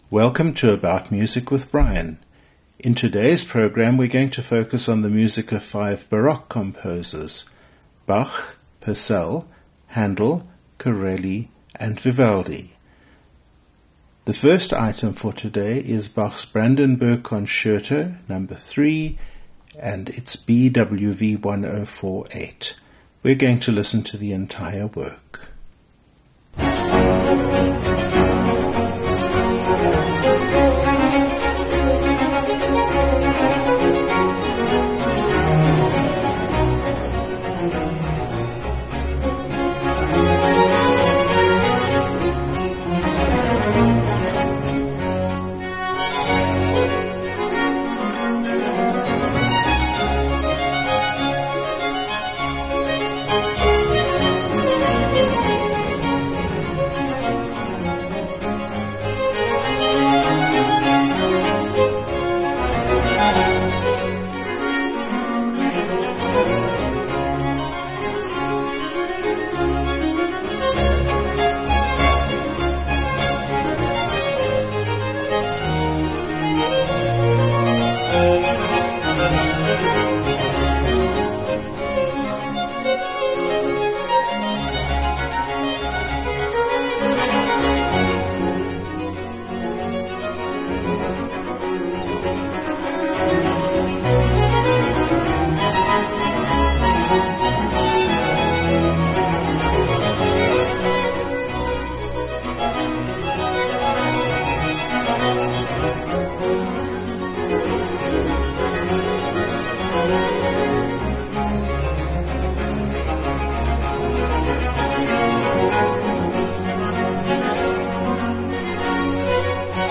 In the next programme in the series, we are looking at the music of the Baroque period, Our composers for this programme are Bach, Purcell, Handel, Corelli, and Vivaldi. Spend an hour with me getting up close and personal with the Baroque sound as composed by these five masters.